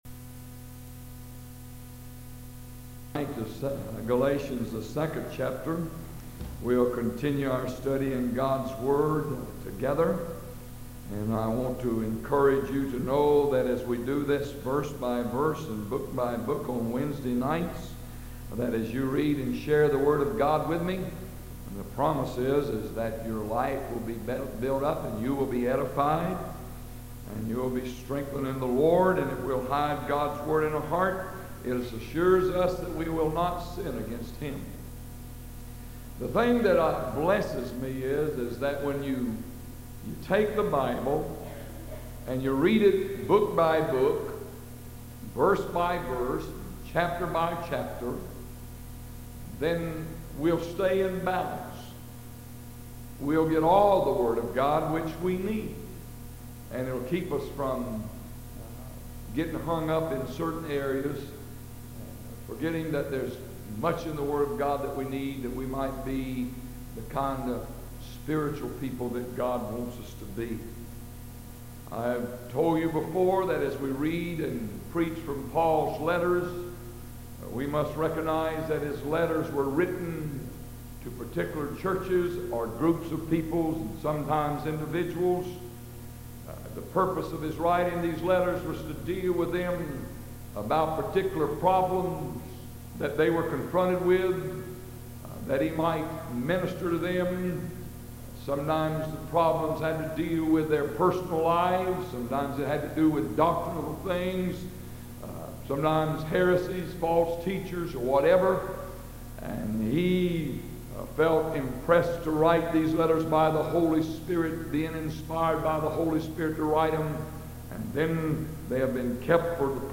Galatians Study – August 28, 1985